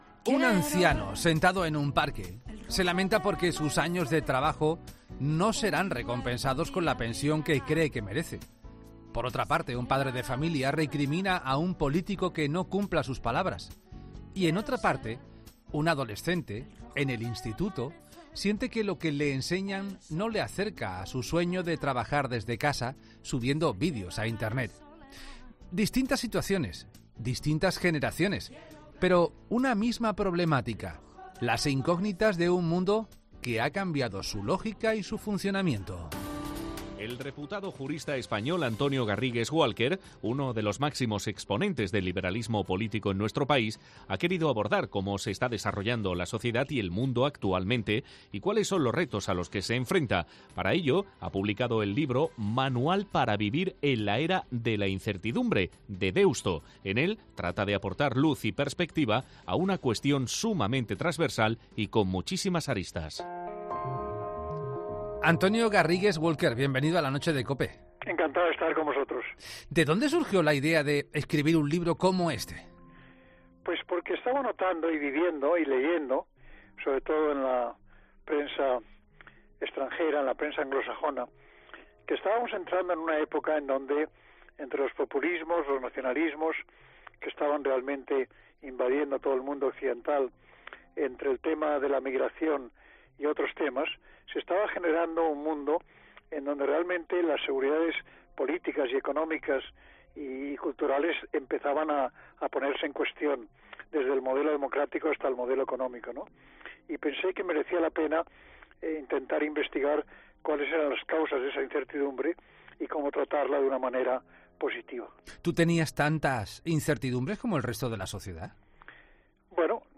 El reputado jurista español presenta su nuevo libro en 'La Noche de COPE'.
Además, en su entrevista en La Noche de COPE ha tratado temas como el futuro del capitalismo.